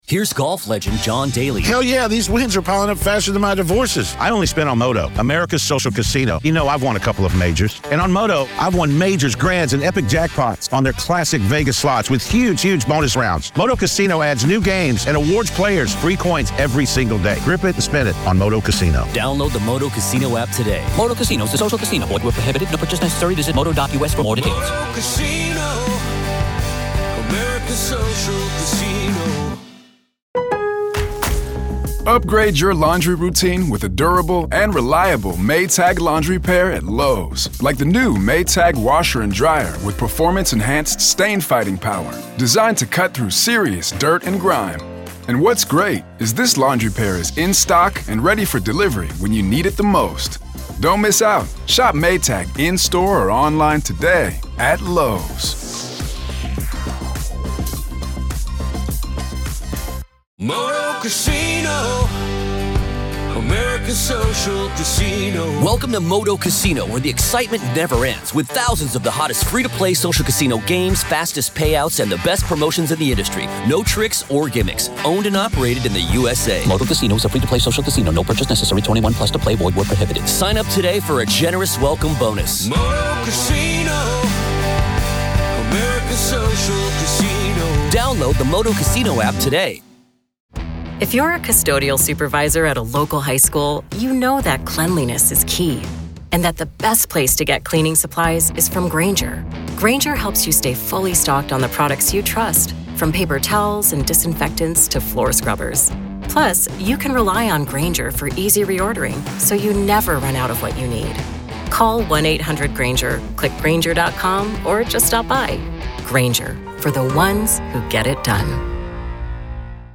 Step inside the courtroom with this riveting clip